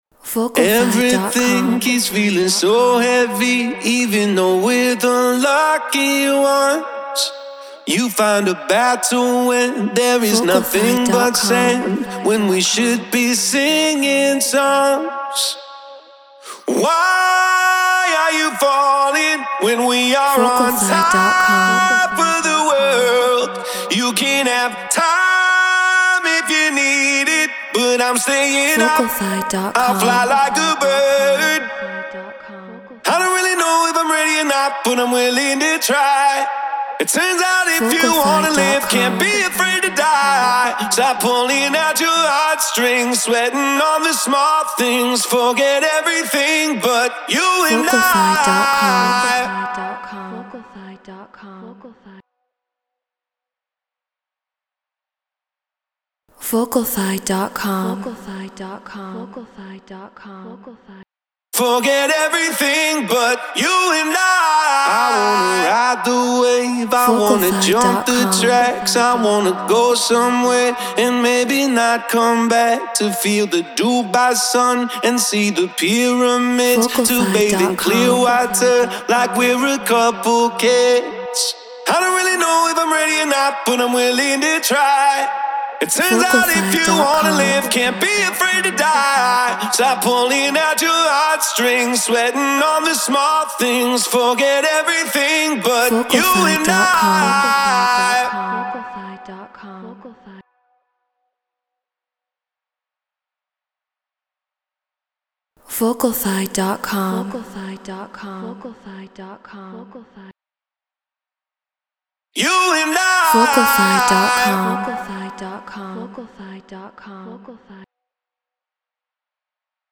Tropical 118 BPM Bmaj
Human-Made
Neumann TLM 103 Apollo Twin Ableton Live Treated Room